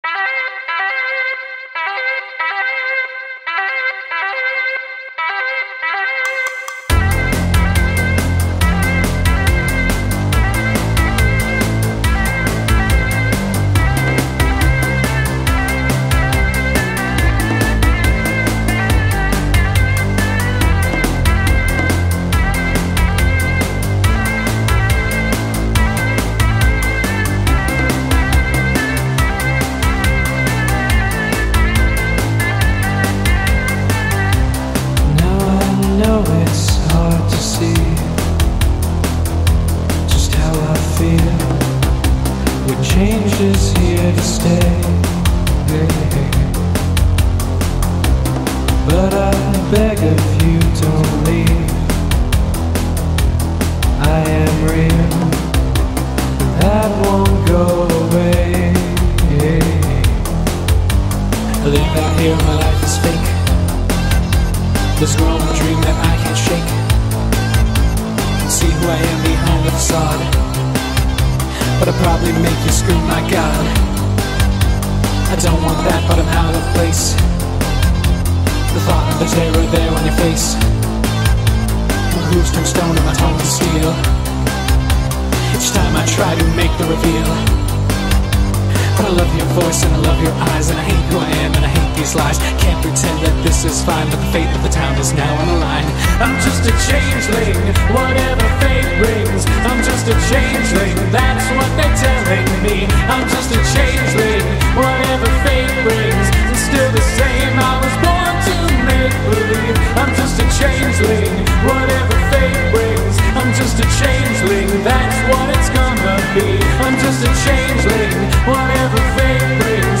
SINGING: Me, duh.